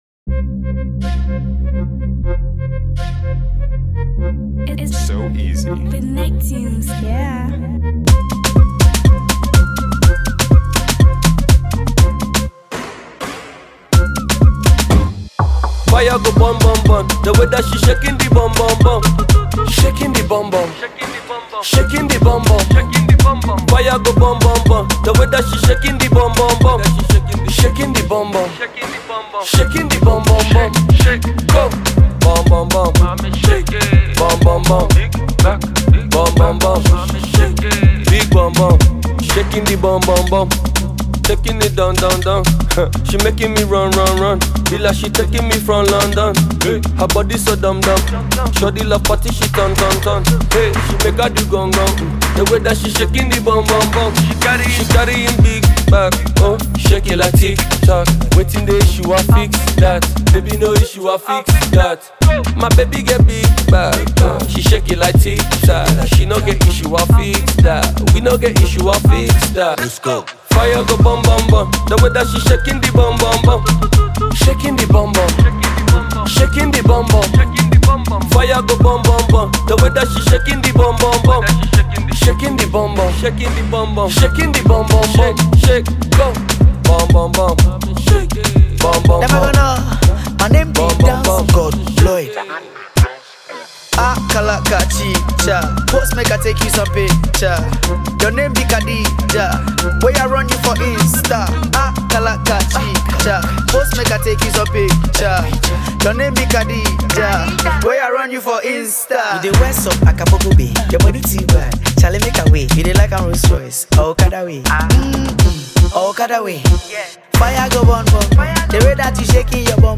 Ghana Music Music
Ghanaian rapper